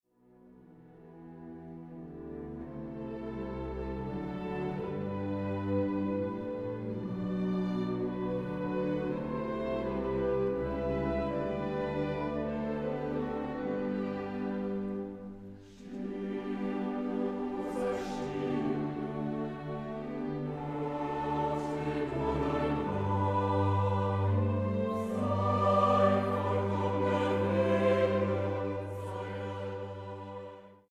Chor, Bläser